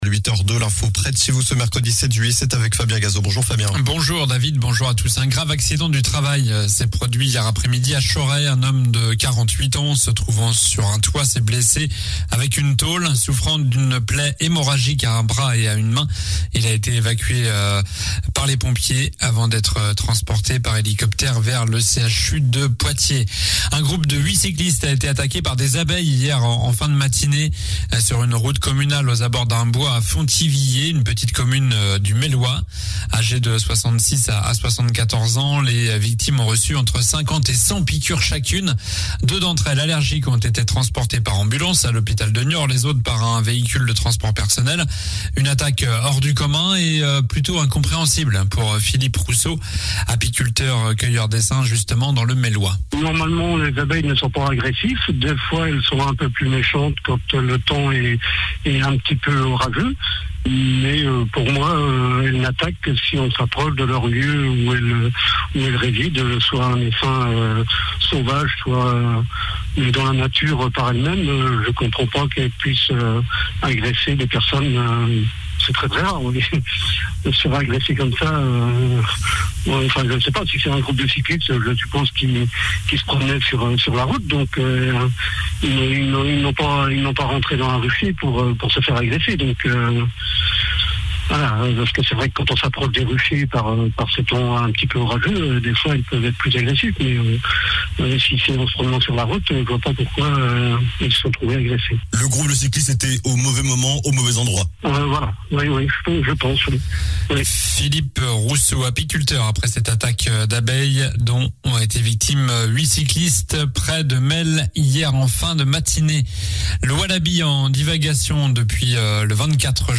Journal du mercredi 07 juillet (matin)